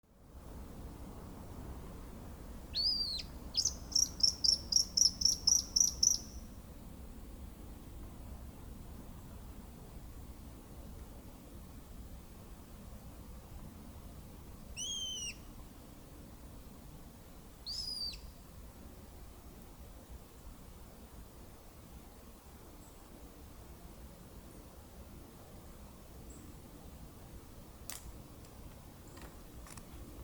Putni -> Pūces ->
Apodziņš, Glaucidium passerinum
Administratīvā teritorijaJūrmala
StatussDzirdēta balss, saucieni